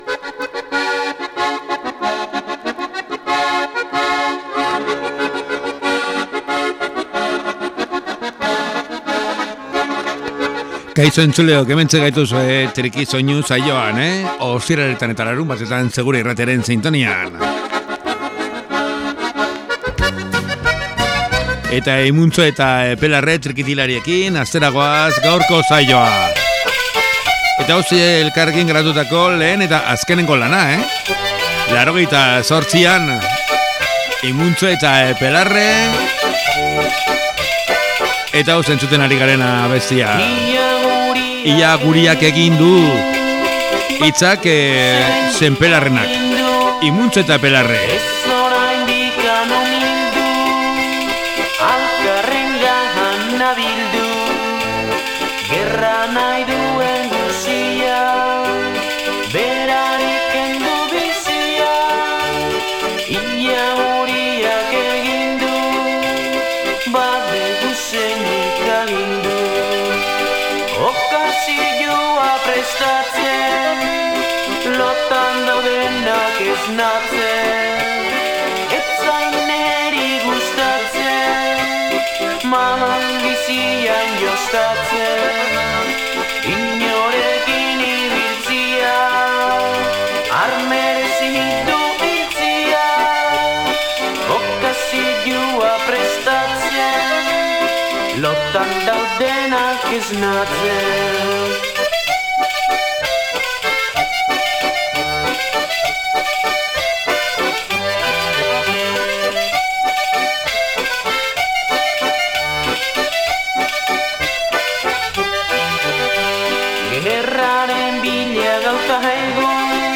TRikisoinu saioan trikitixa pieza ederrak entzuteko aukera paregabea.